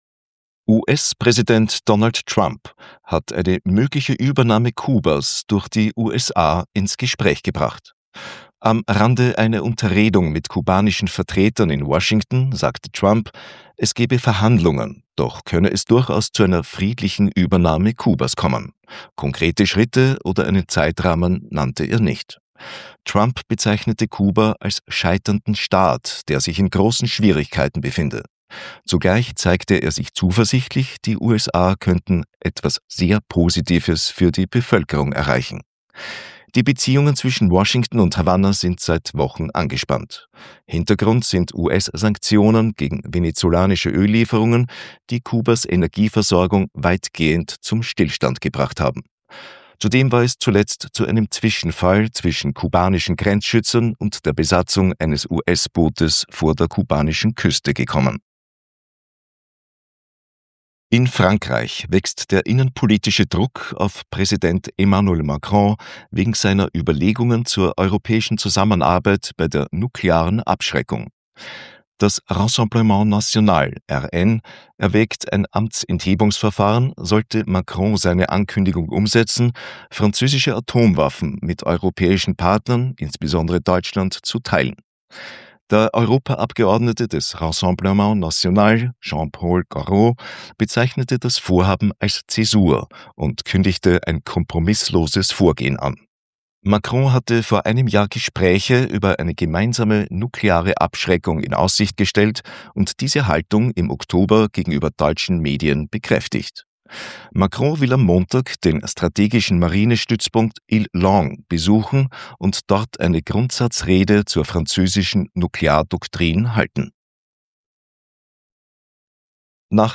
Kontrafunk Wochenrückblick 28.2.2026 – Nachrichten vom 28.2.2026
Hören Sie ausgewählte Beiträge aus „Kontrafunk aktuell“ und „18/20“ im Wochenrückblick.